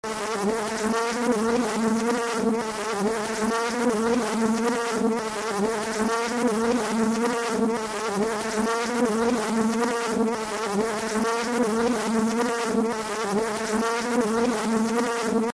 Звук летящего шмеля
• Категория: Шмель
• Качество: Высокое